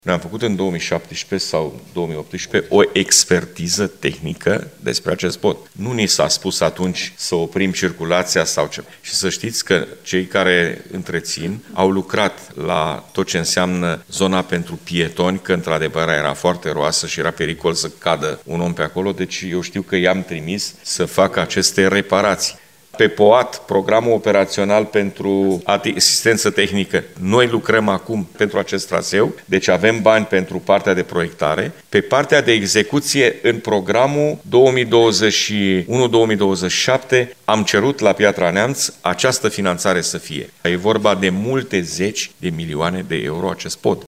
În replică, președintele Consiliului Județean Suceava GHEORGHE FLUTUR a precizat că singura soluție pentru refacerea podului este atragerea de fonduri europene, deoarece investițiile totale sunt extrem de costisitoare.